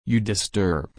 /dɪsˈtɜːb/